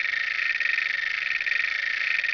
wee_car.wav